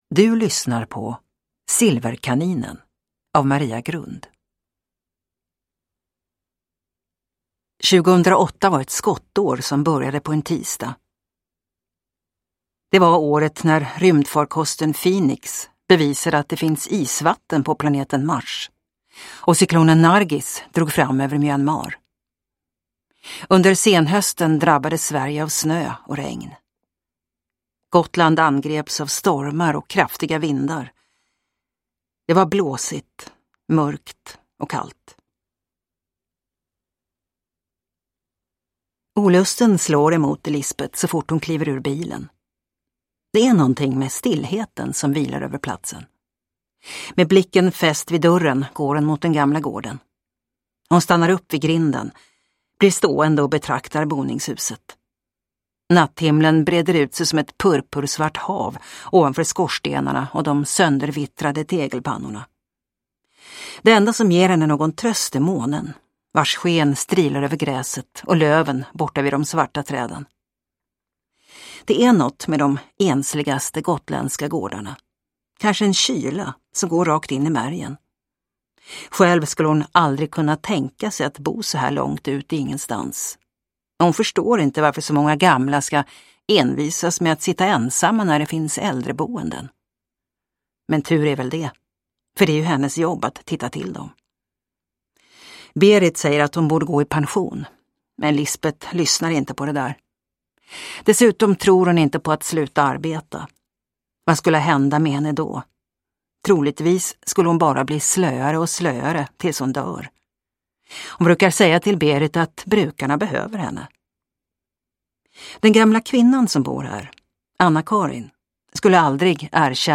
Silverkaninen (ljudbok) av Maria Grund